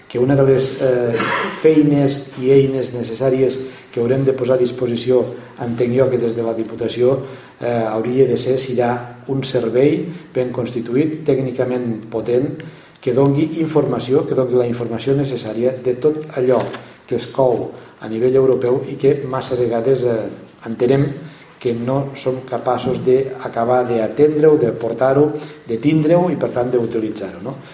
El president de la Diputació de Lleida, Joan Reñé, ha presidit l’acte de cloenda de la jornada sobre Polítiques Europees 2014-20 que ha acollit la incubadora d’empreses de Bell-lloc